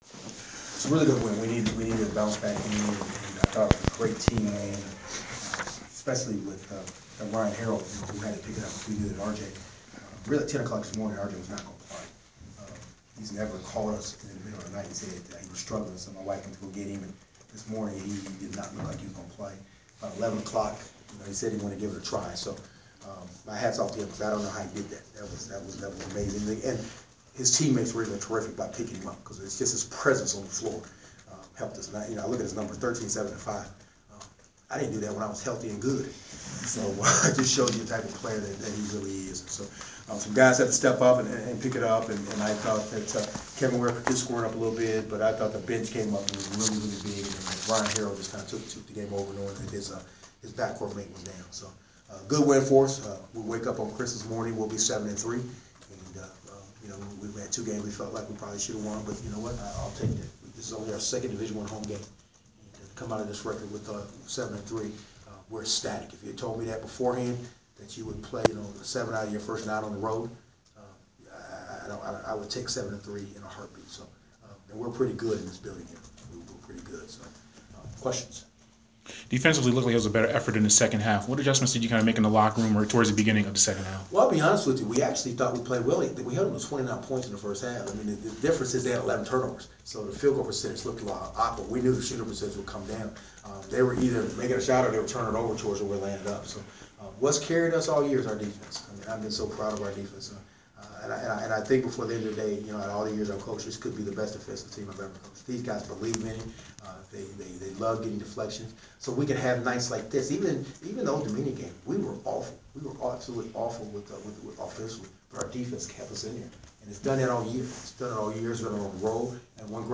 postgame presser (12/21/14)